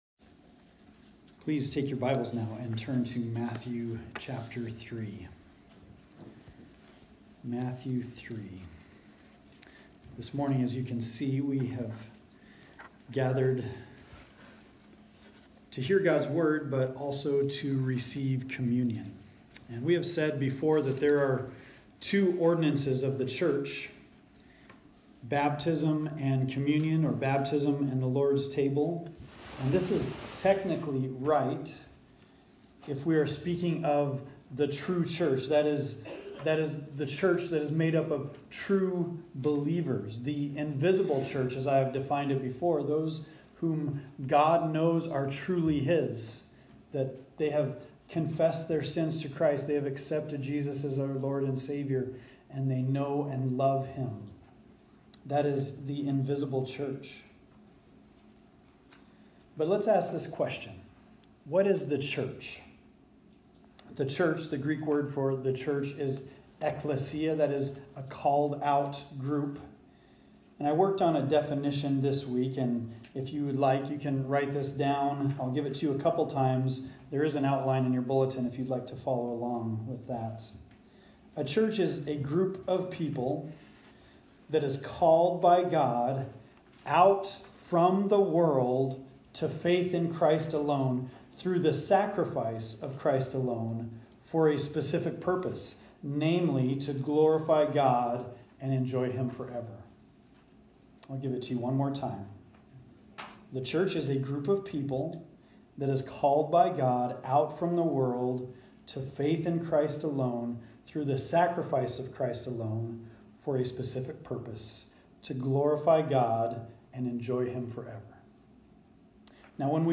Communion Meditation